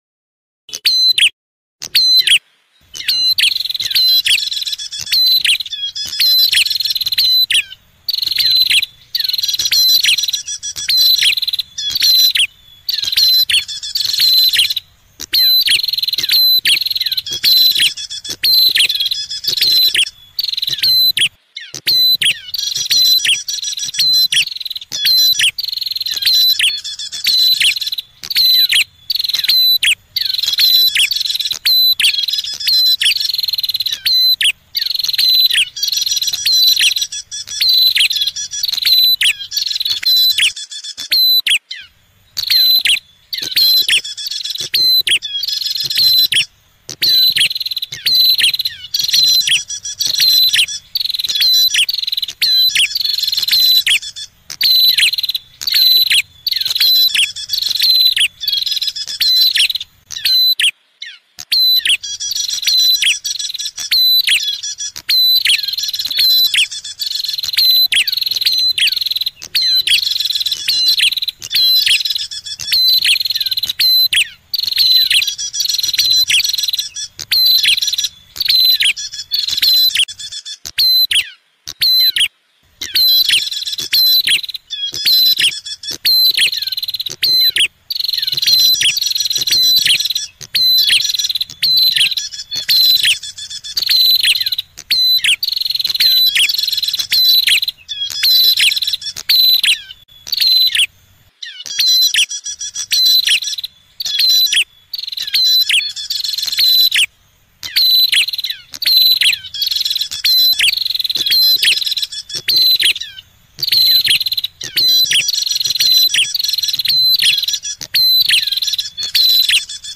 Unduh suara burung Prenjak Kepala Merah liar dalam format mp3.
Suara prenjak kepala merah
suara-prenjak-kepala-merah-id-www_tiengdong_com.mp3